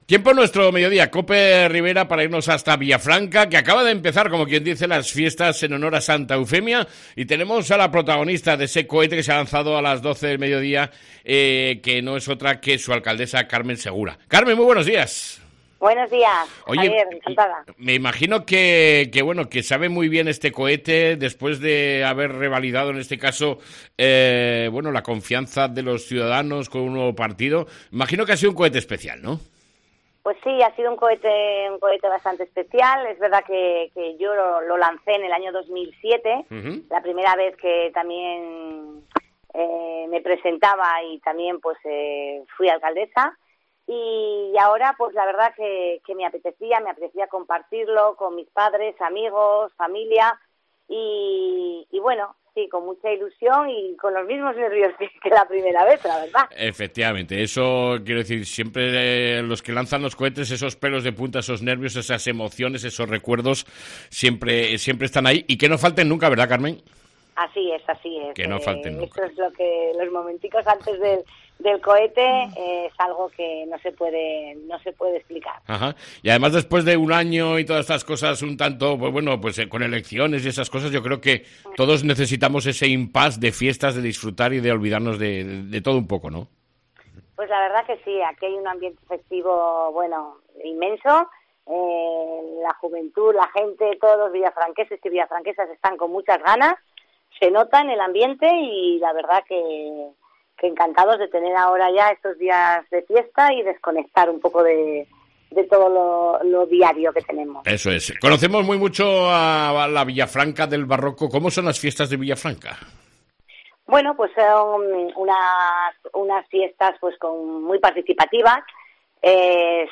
ENTREVISTA CON LA ALCALDESA DE VILLAFRANCA, Mª CARMEN SEGURA